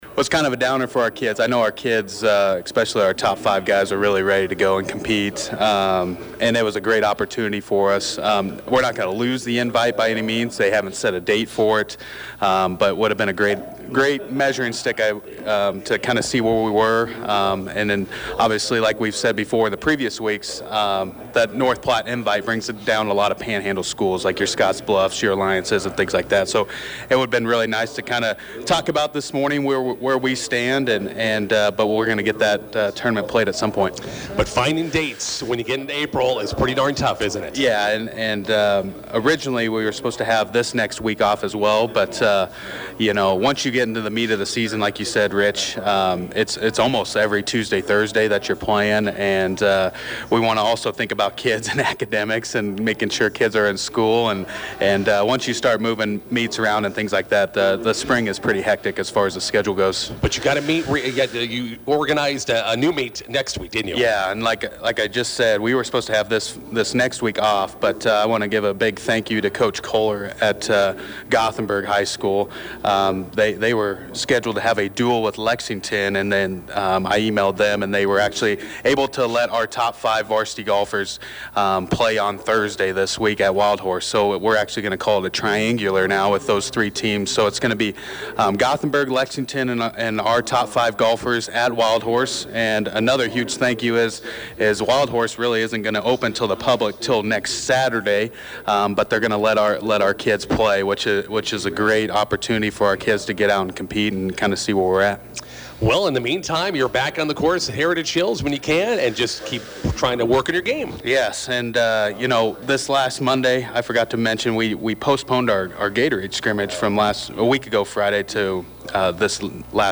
INTERVIEW: Bison golfers open spring season today at Gothenburg.